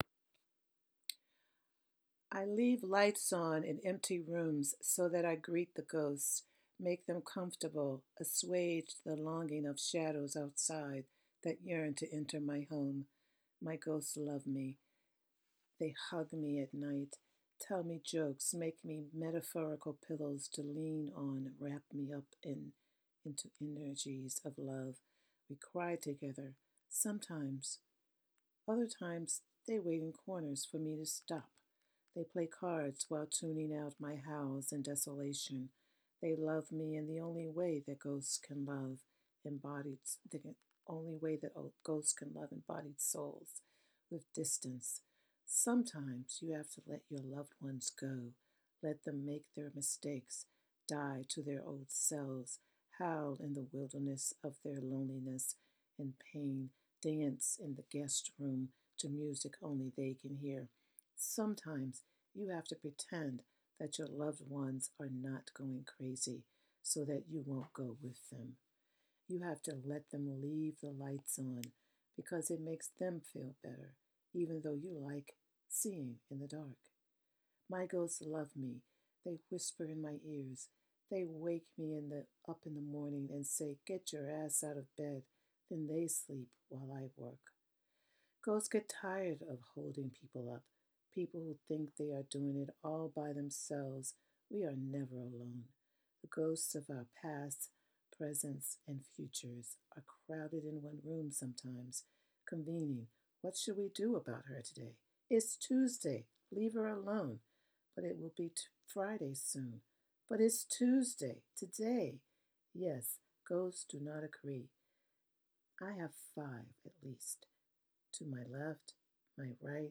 Here is a poem I drafted today. I'm reading the raw draft.